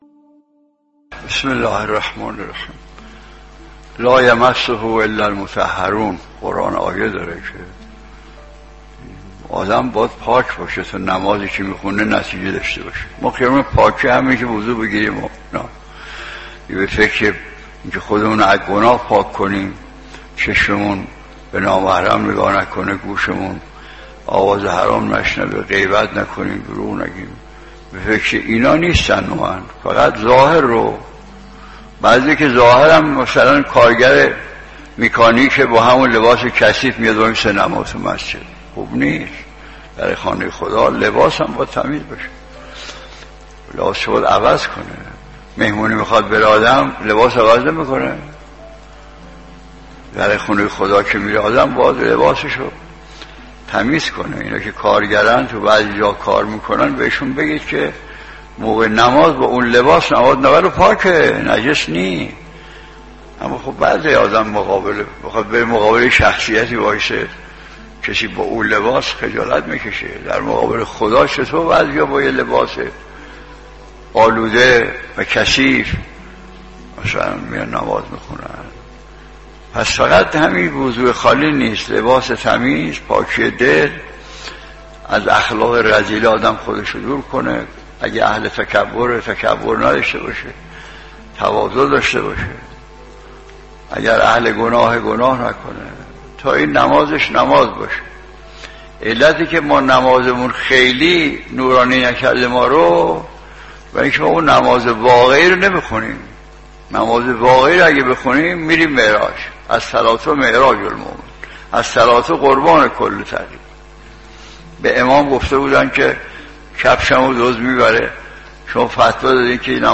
درس اخلاق | نمازی که انسان را به معراج نمی‌بَرد